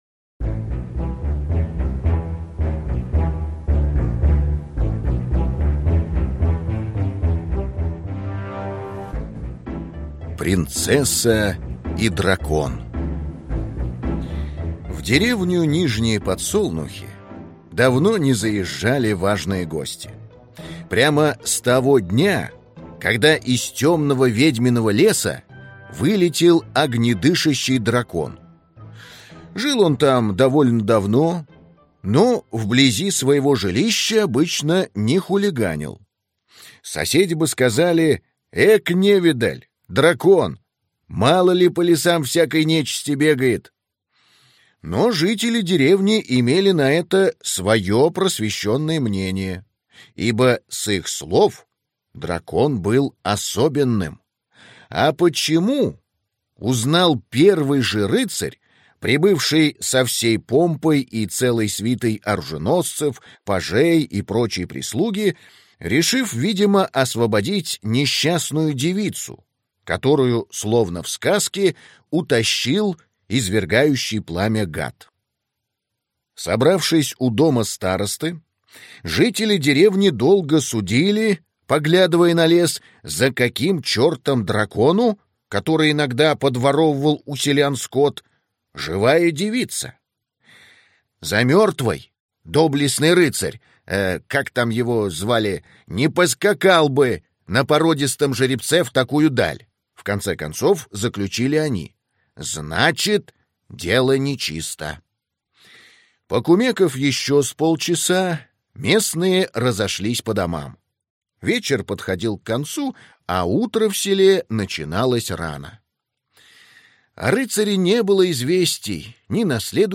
Аудиокнига Хроники Дракона в Нижних Подсолнухах | Библиотека аудиокниг